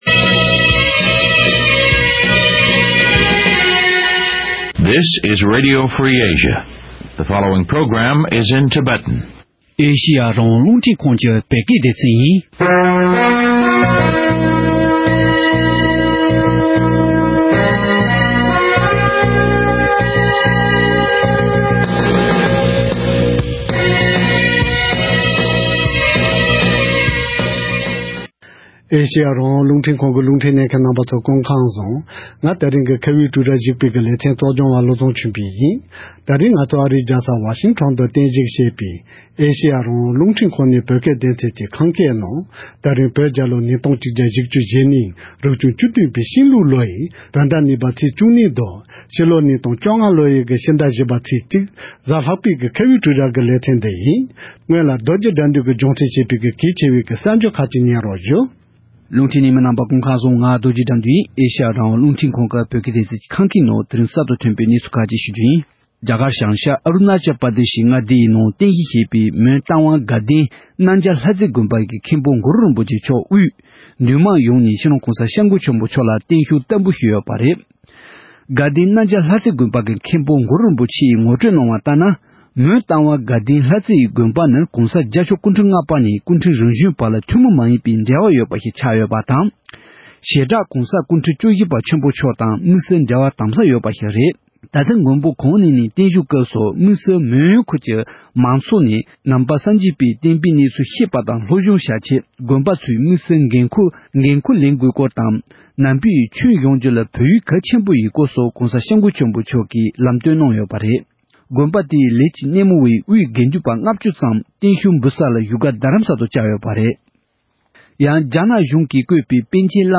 ༄༅། །ཐེངས་འདིའི་ཁ་བའི་གྲོས་རྭ་ཞེས་པའི་ལེ་ཚན་འདིའི་ནང་། བོད་ཀྱི་ཆབ་སྲིད་བཙོན་པ་གྲགས་ཅན་སྤྲུལ་སྐུ་བསྟན་འཛིན་བདེ་ལེགས་རིན་པོ་ཆེ་མཆོག་སྤྱི་ལོ་༢༠༠༢ལོའི་སྤྱི་ཟླ་༤ཚེས་༧ཉིན་རྒྱ་ནག་གཞུང་གིས་མ་ཉེས་ཁ་གཡོག་བྱས་ནས་ཚེ་བཙོན་གྱི་ཁྲིམས་ཐག་བཅད་ནས་ལོ་ངོ་༡༣འཁོར་པའི་སྐབས་དེ་ལ་རིན་པོ་ཆེ་མཆོག་གི་ད་ལྟའི་སྐུ་གཟུགས་གནས་སྟངས་སོགས་ཀྱི་སྐོར་ལ་འབྲེལ་ཡོད་ཁག་ཅིག་དང་གླེང་མོལ་ཞུས་པར་གསན་རོགས་གནང་།།